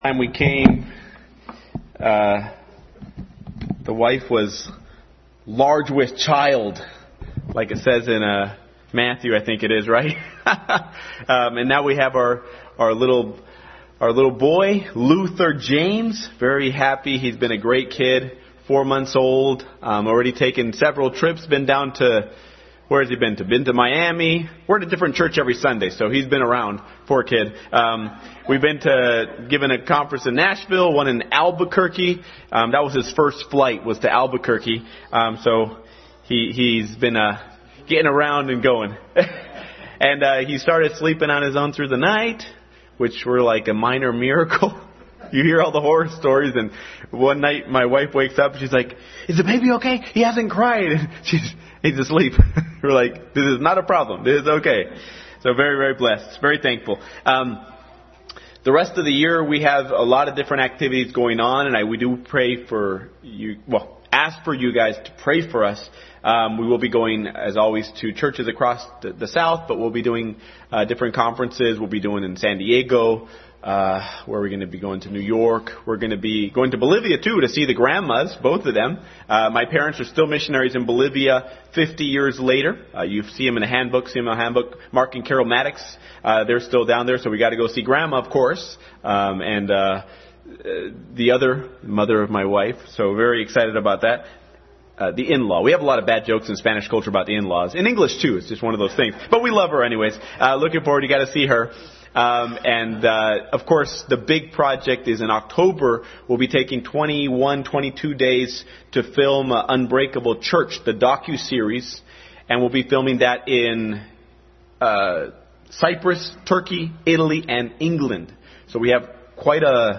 Passage: Ruth Chapters 1-4 Service Type: Family Bible Hour